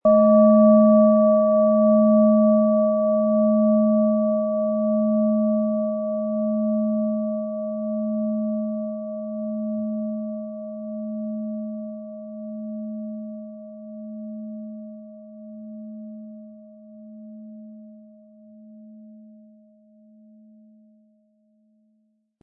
Tibetische Herz-Bauch- und Becken-Klangschale, Ø 13,8 cm, 400-500 Gramm, mit Klöppel
Seit Jahrhunderten stellt man in Asien diese Art der wunderschön klingenden Klangschalen in Manufakturen her.
Um den Original-Klang genau dieser Schale zu hören, lassen Sie bitte den hinterlegten Sound abspielen.